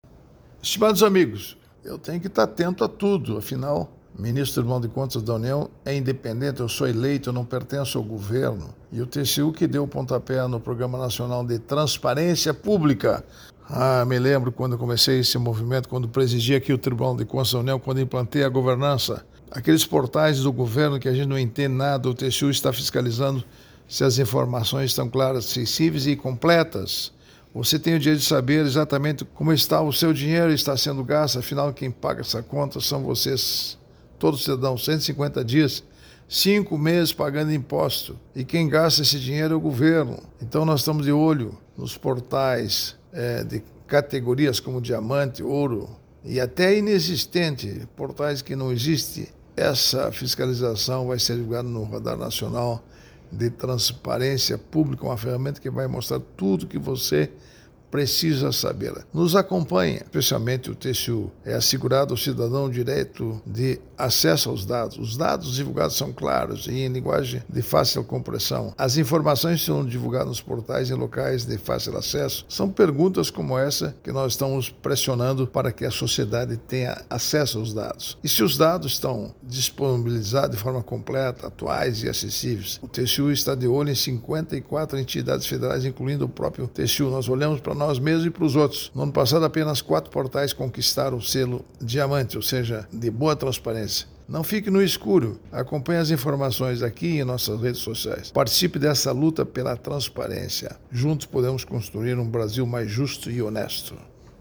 04-Ministro-prog-radio-cidadao-tem-direito-a-informacao.mp3